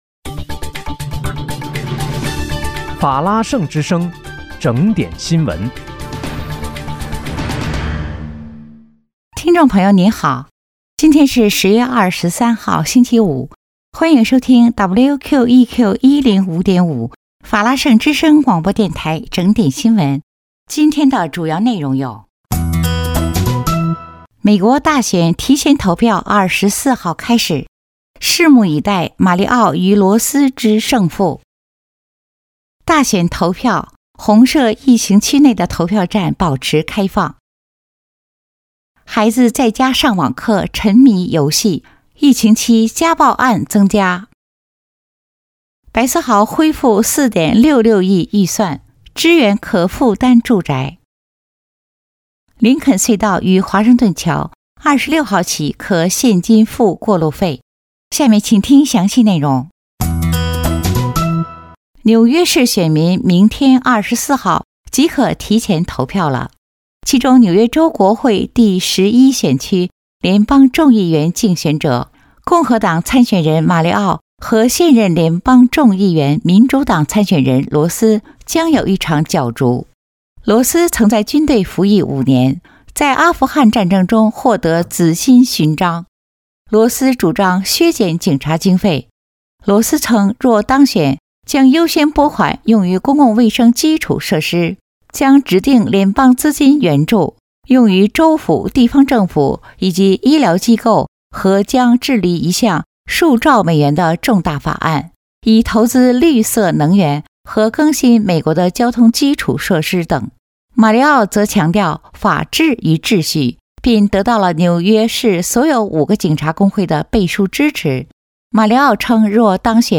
10月23日（星期五）纽约整点新闻